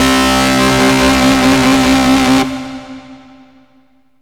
SYNTH LEADS-1 0011.wav